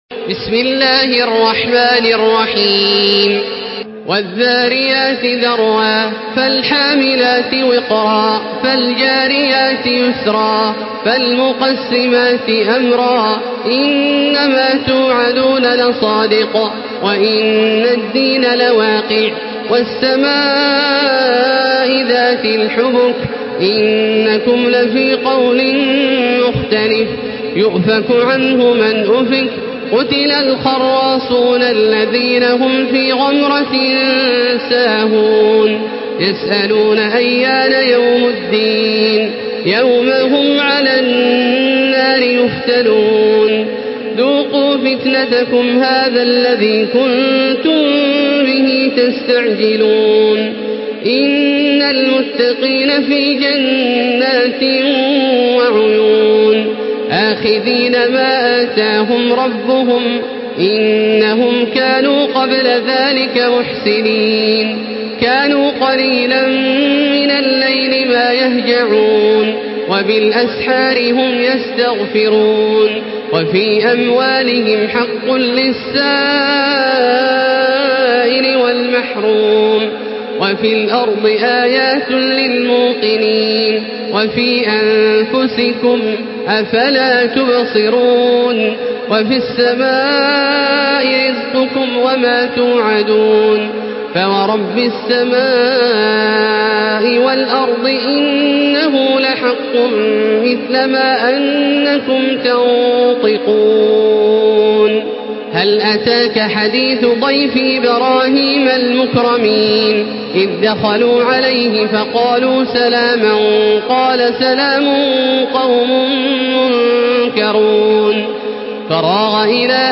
Surah Zariyat MP3 by Makkah Taraweeh 1435 in Hafs An Asim narration.
Murattal